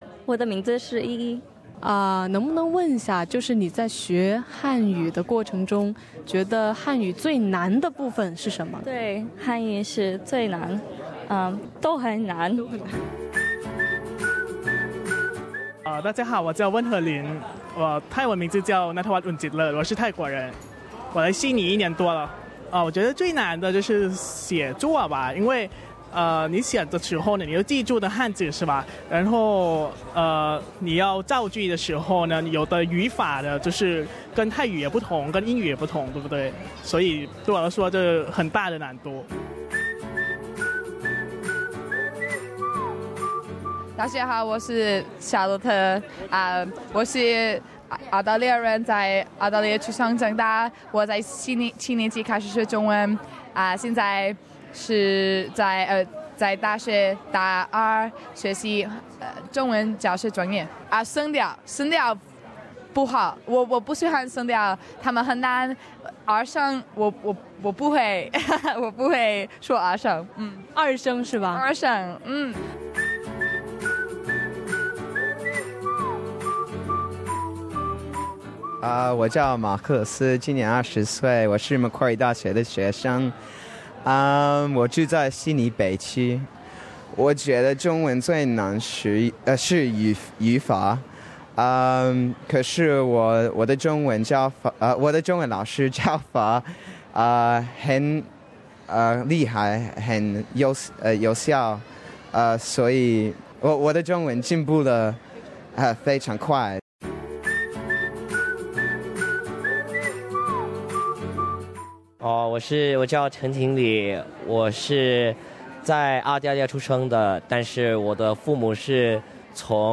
在紧张激烈的比赛之后，大学生参赛学生在接受本台采访时，吐露了中文不易学的心声，我们一起来听一听：